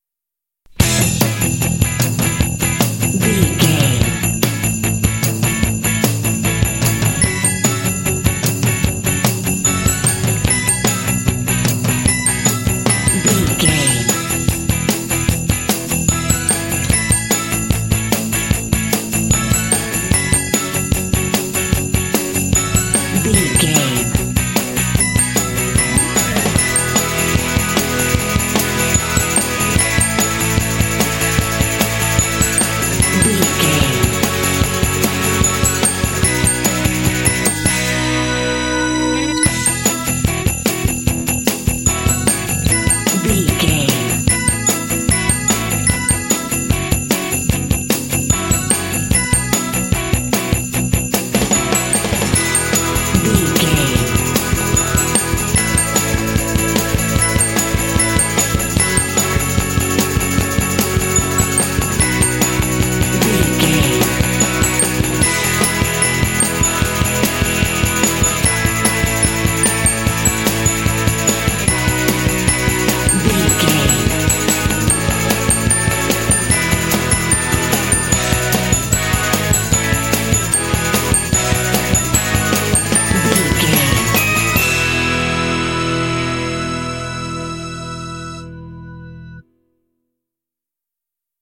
Ionian/Major
uplifting
bouncy
festive
drums
bass guitar
strings
electric guitar
electric organ
contemporary underscore